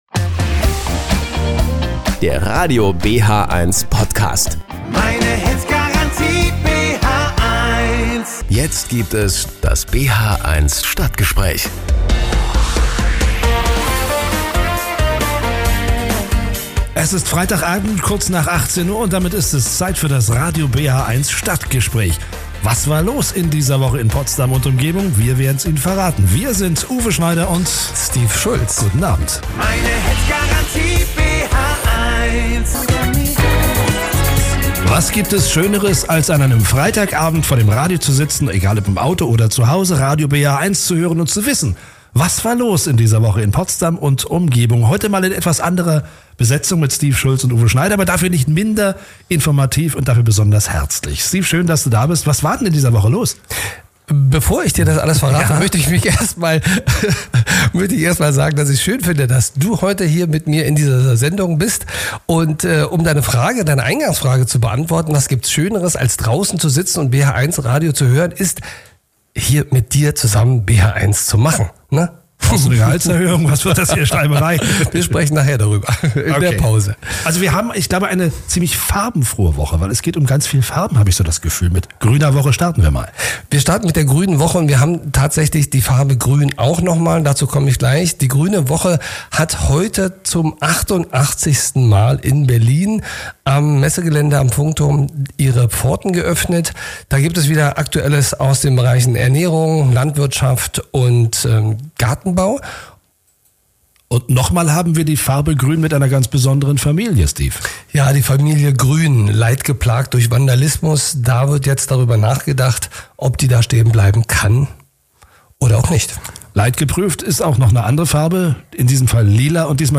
im Dialog zu den Themen der Woche.